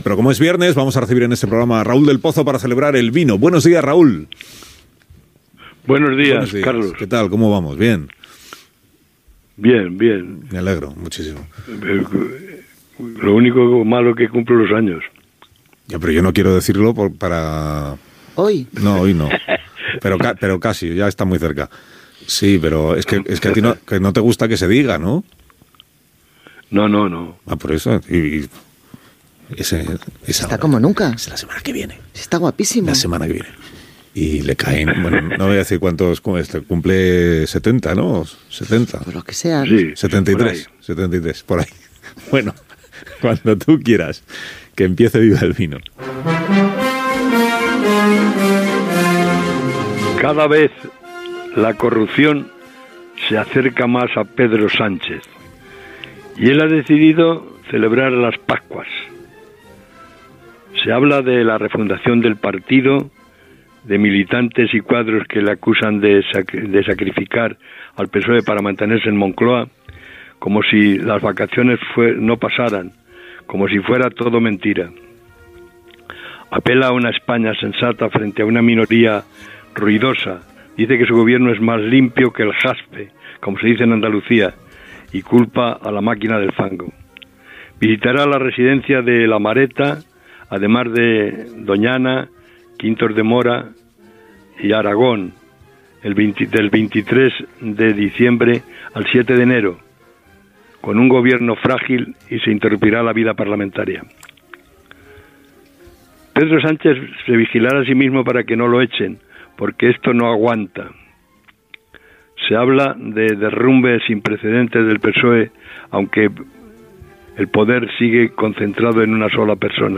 Info-entreteniment
FM